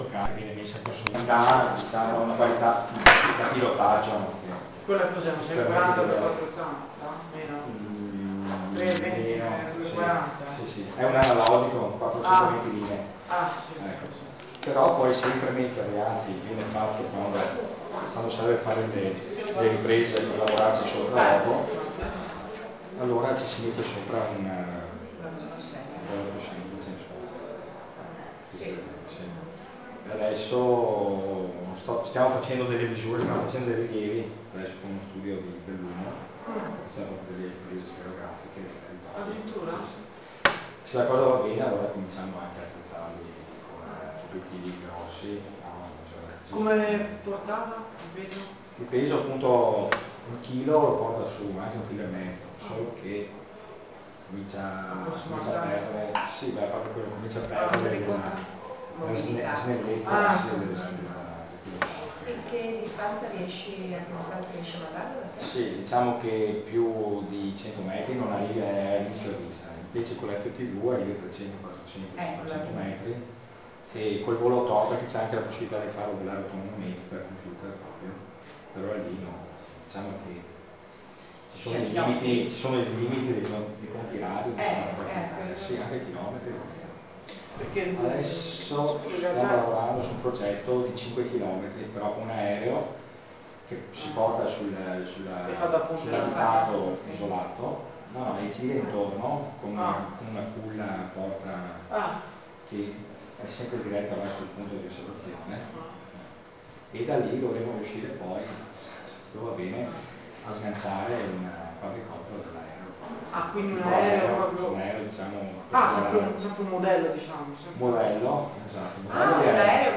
volo nel cortile dei Tolentini